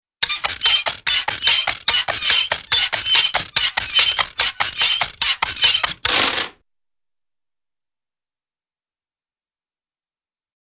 Metallic Squeak and Click Rhythm - Bouton d'effet sonore